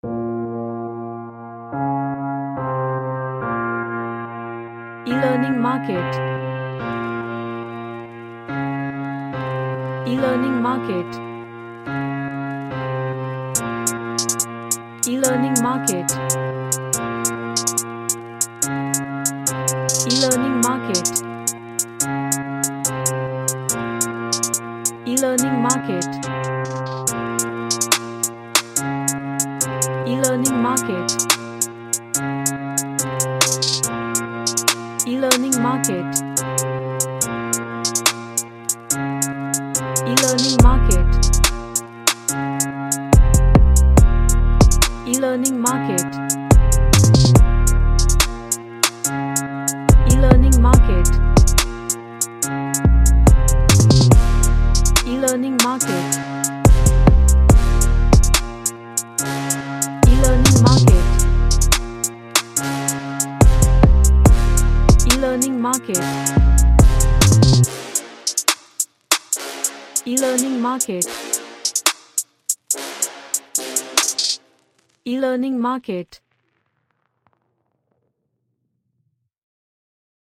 A dark ninja type drill beat
Dark / Somber